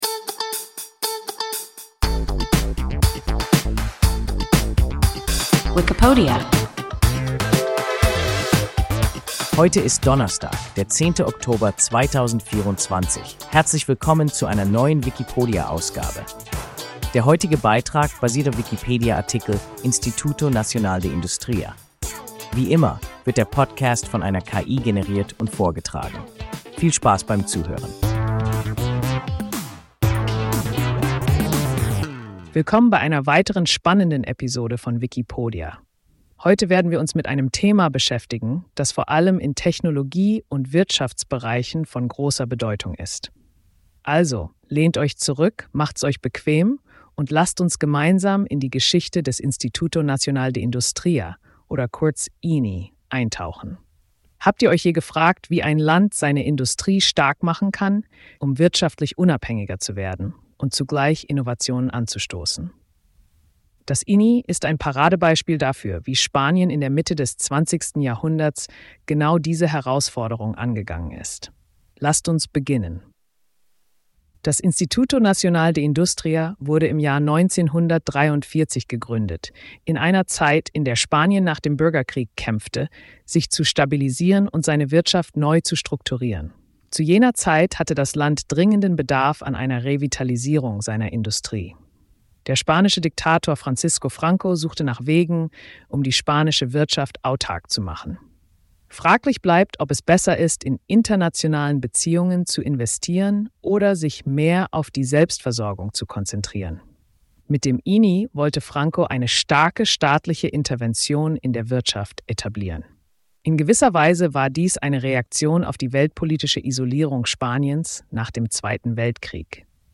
Instituto Nacional de Industria – WIKIPODIA – ein KI Podcast